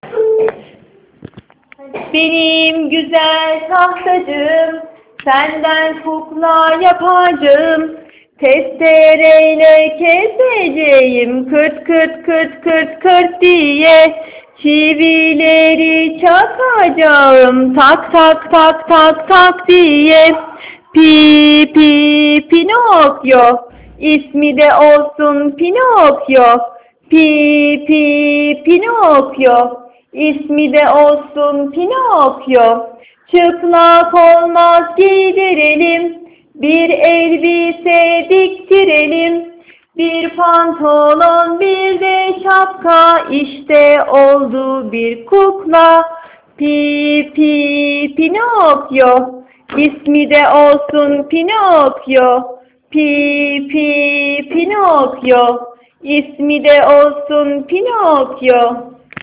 Kendi sesimle söylediğim pinokyo şarkısı..